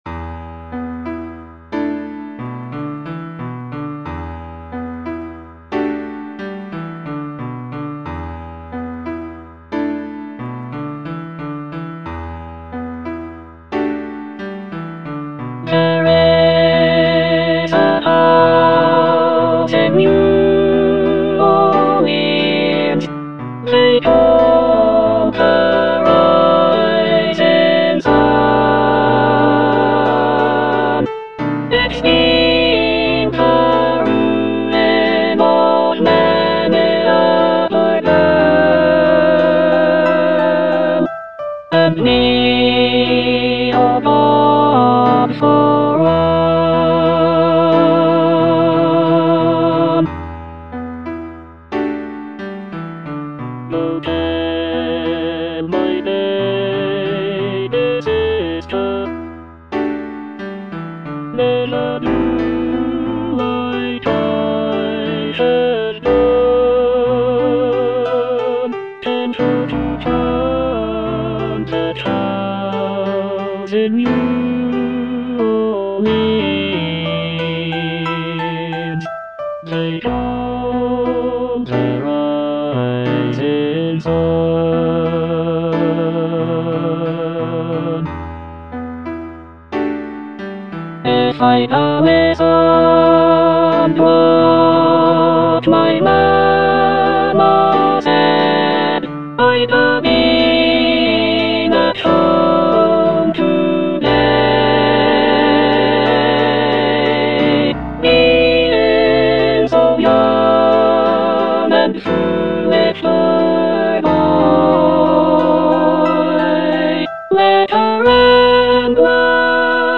(alto II) (Emphasised voice and other voices)